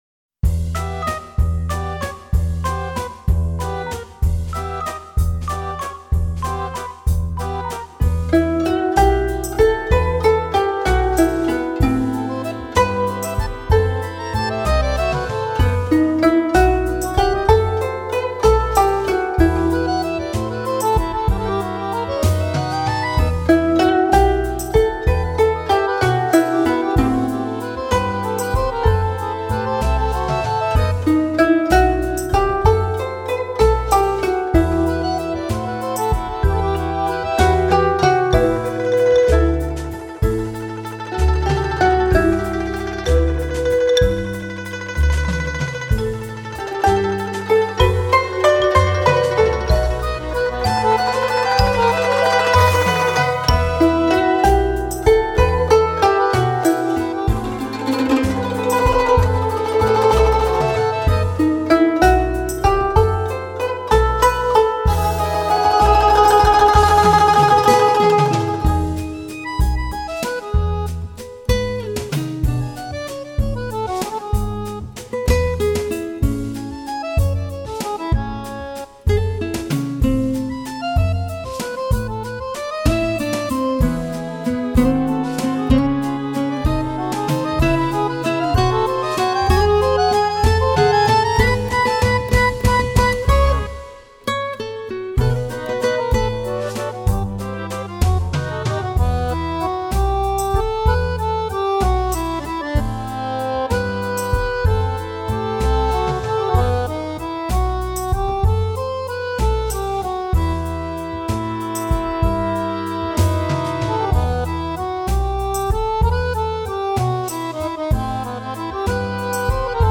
古筝
这是一张京港音乐人联手打造的新民乐力作。